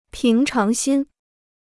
平常心 (píng cháng xīn) Free Chinese Dictionary